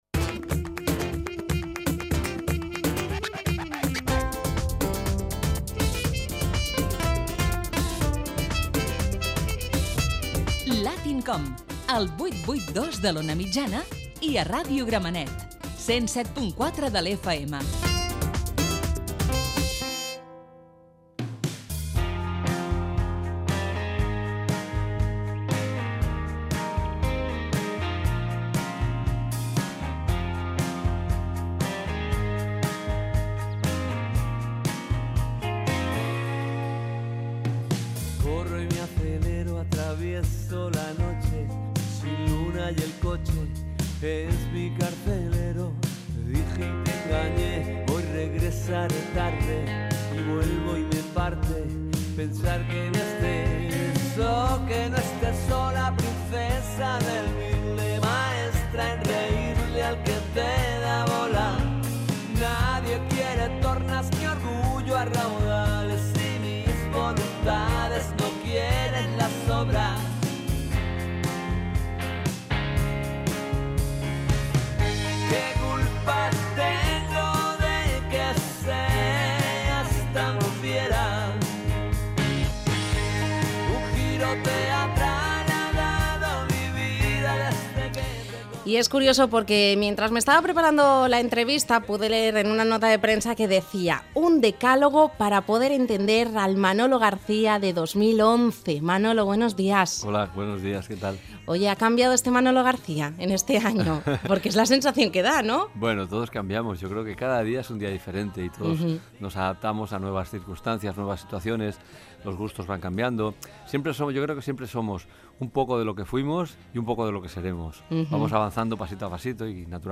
Indicatiu del programa, fragment d'una entrevista al cantant Manolo García
Entreteniment